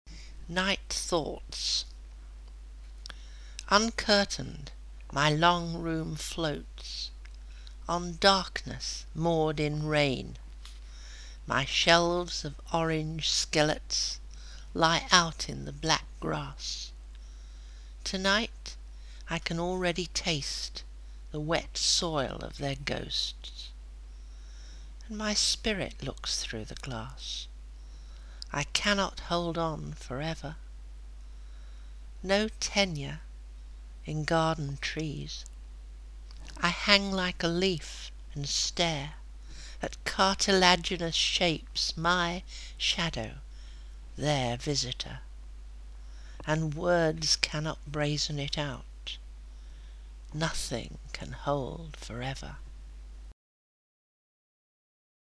Elaine Feinstein reading her own poetry